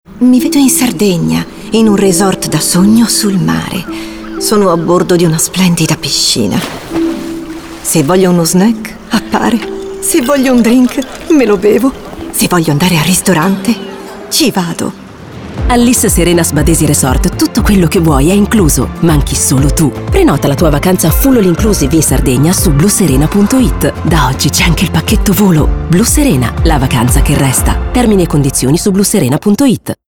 -Locale chiuso fornito di pannelli fonoassorbenti